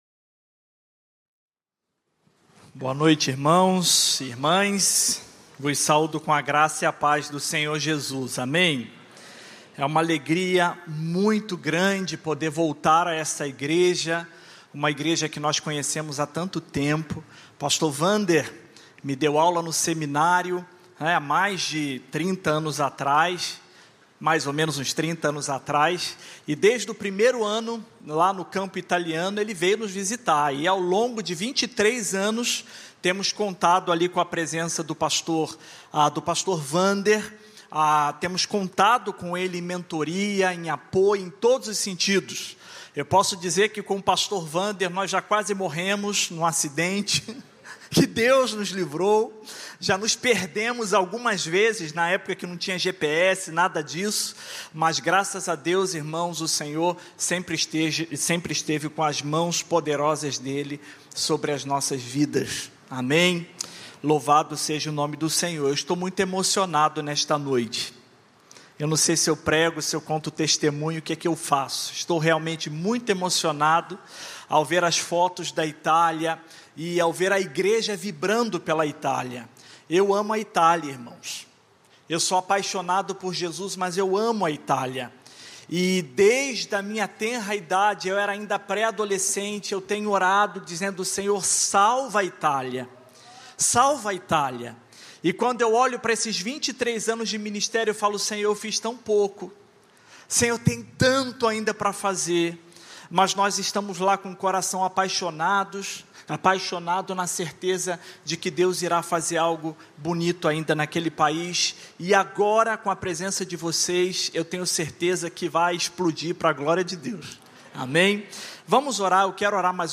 Mensagem apresentada
na Igreja Batista do Recreio.